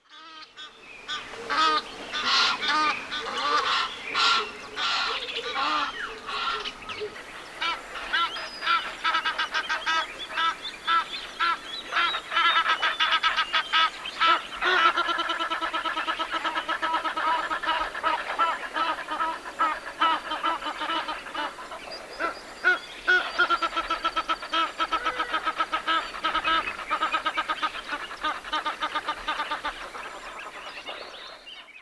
Nilgans Ruf
• Sie können bei Gefahr laut trompetend schreien.
Nilgans-Ruf-Voegel-in-Europa.mp3